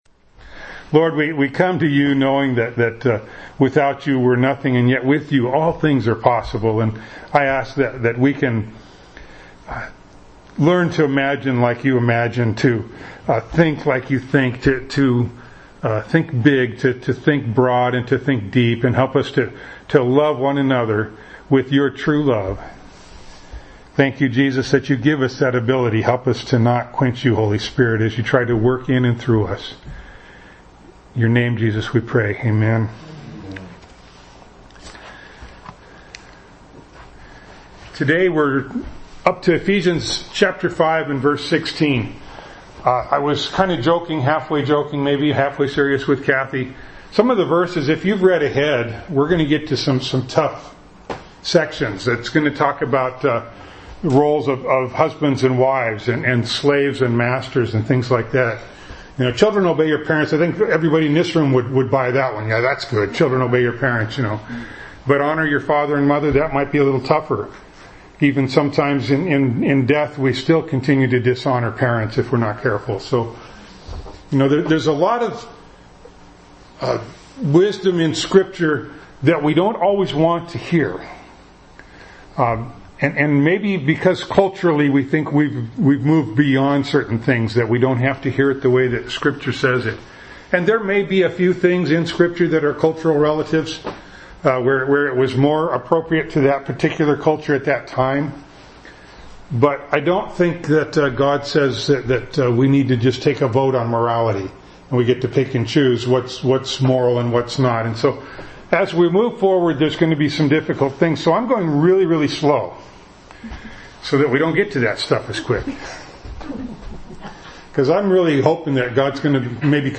Passage: Ephesians 5:16 Service Type: Sunday Morning